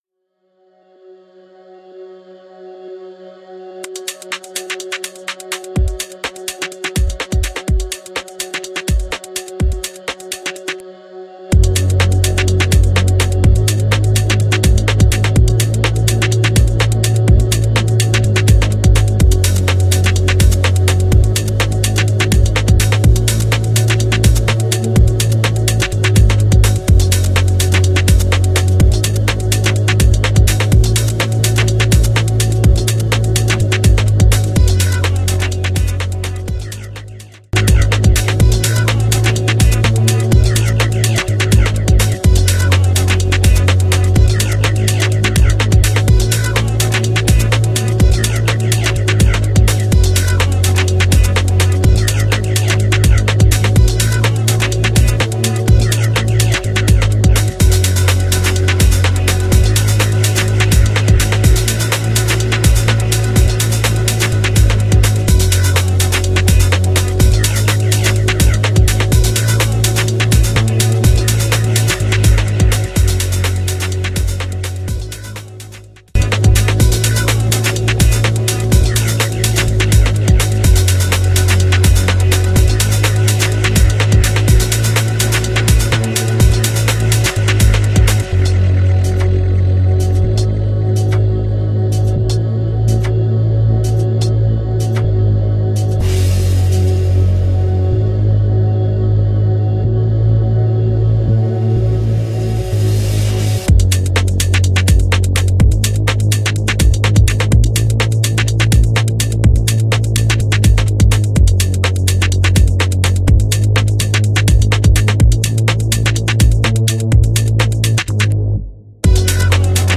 Breaks